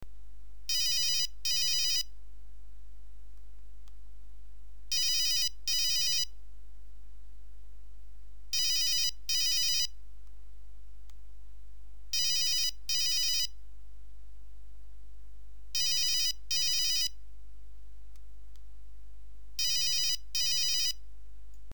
Звук Мелодии